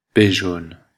Ääntäminen
Synonyymit blanc-bec Ääntäminen Bruxelles, Belgique: IPA: [be.ʒon] Tuntematon aksentti: IPA: /be.ʒɔn/ Haettu sana löytyi näillä lähdekielillä: ranska Käännös 1. aprendiz {m} 2. novicio {m} 3. pipiolo {m} 4. novato {m} Suku: m .